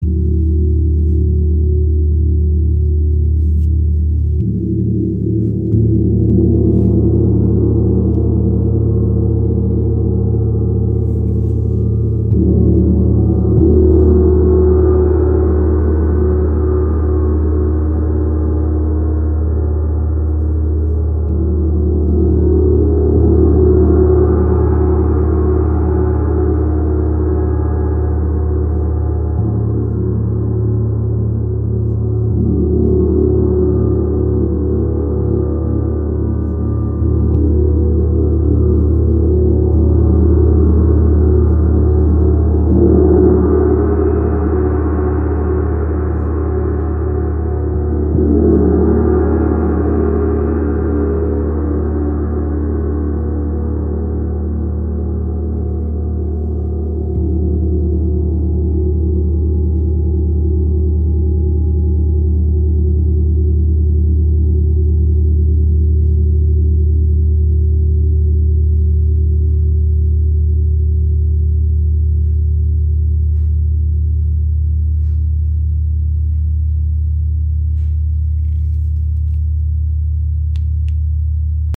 Tempel Gong | Mandala | 80 cm im Raven-Spirit WebShop • Raven Spirit
Klangbeispiel
Dieser ganz besondere Tempel Gong hat wie der Chao oder Tam Tam Gong einen gehämmerten Rand und wurde in Nepal im Kathmandu-Tal erschaffen.